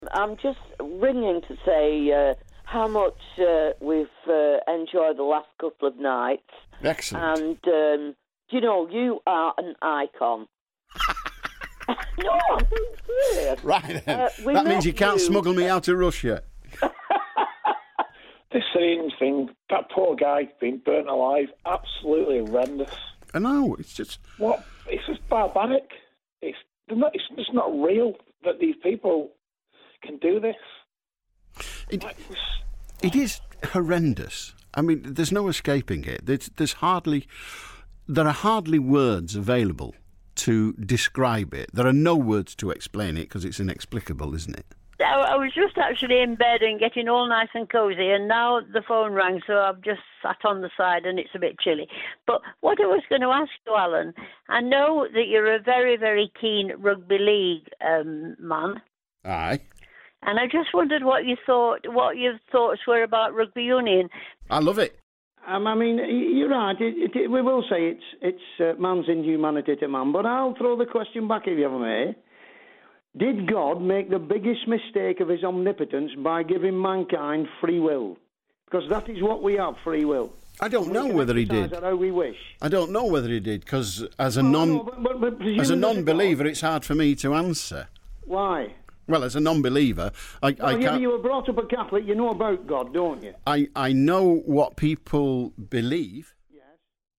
busy on the phones tonight - click to hear some of the calls